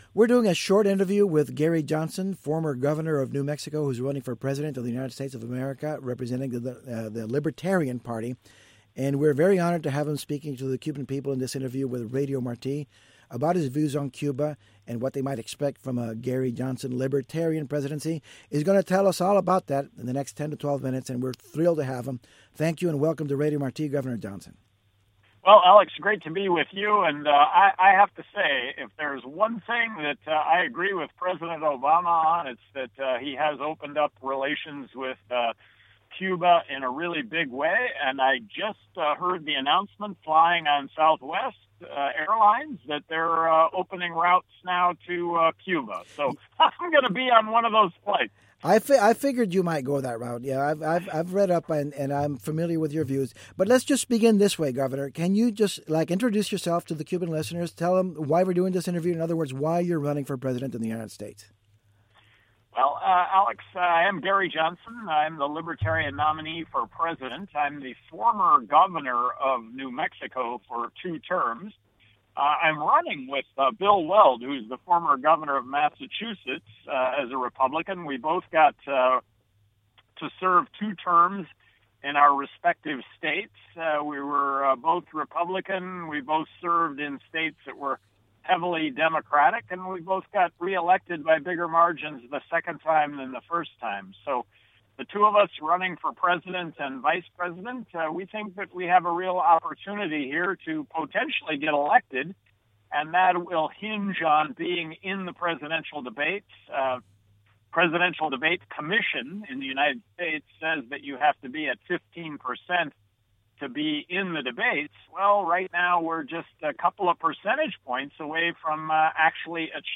Candidato libertario a la presidencia de EEUU habla con Radio Martí
Entrevista a Gary Johnson